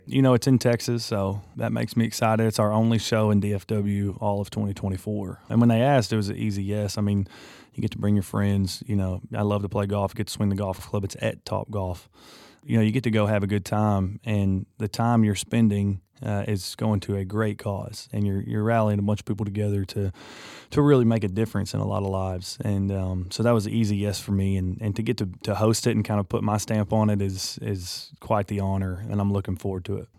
Audio / Parker McCollum talks about hosting and headlining the ACM Lifting Lives concert in Texas in May.